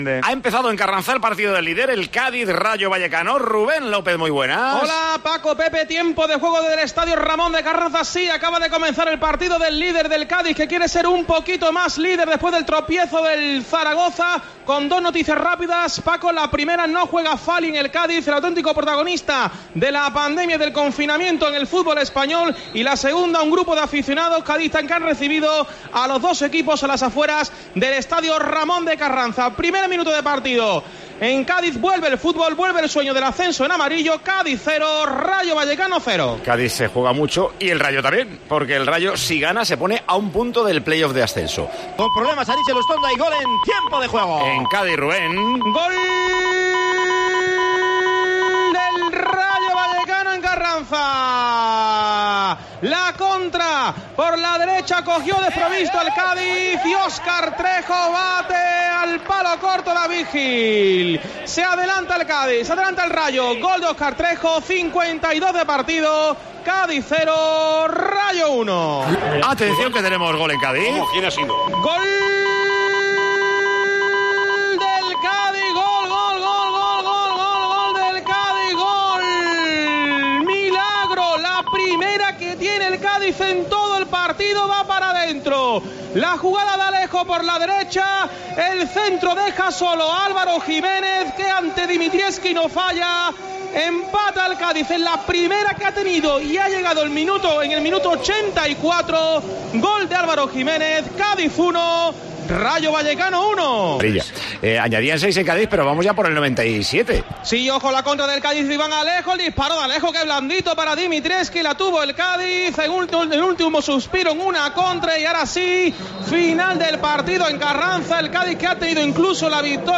Los goles de Trejo y Álvaro Giménez sonaron en Tiempo de Juego destacando la importancia del punto para el equipo cadista.